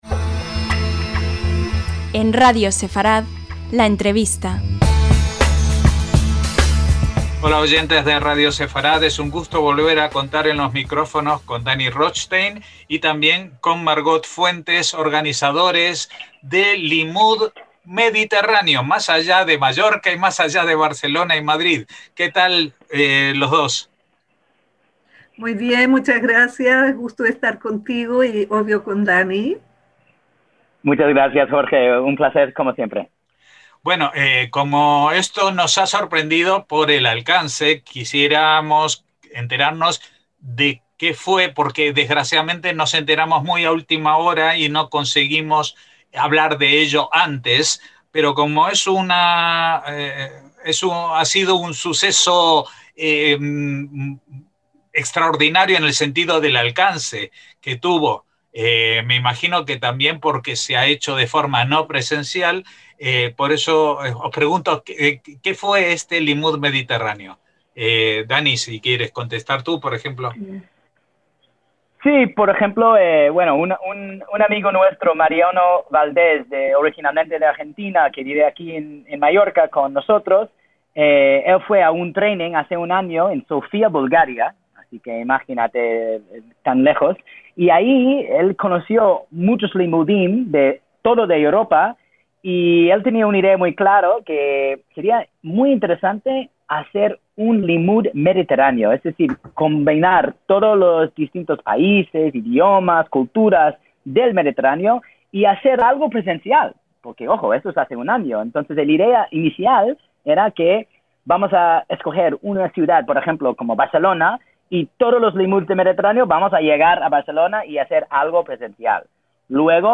LA ENTREVISTA - El pasado domingo 29 de noviembre tuvo lugar una Limmud muy especial, online y transnacional, que conectó a individuos interesados en la cultura judía de varios países del Mediterráneo, de España a Israel, pasando por Francia, Italia, Grecia y Turquía.